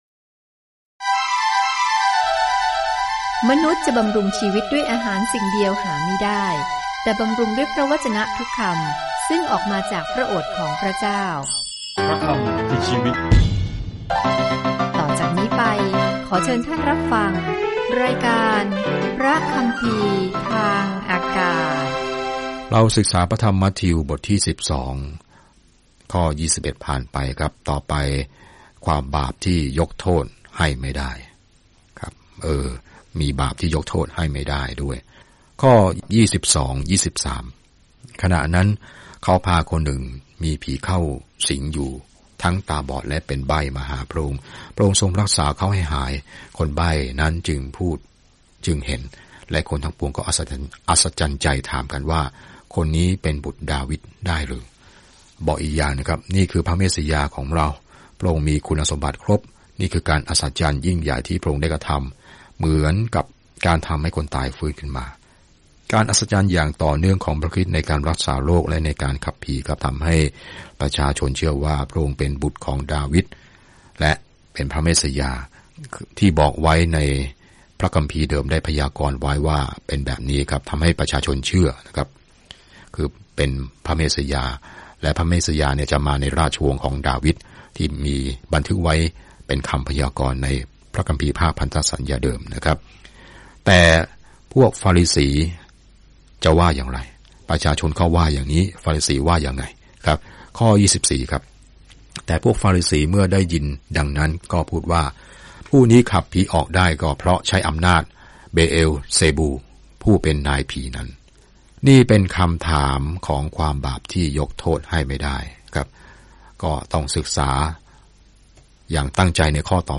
เดินทางทุกวันผ่านมัทธิวในขณะที่คุณฟังการศึกษาด้วยเสียงและอ่านข้อที่เลือกจากพระวจนะของพระเจ้า